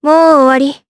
Tanya-Vox_Victory_jp.wav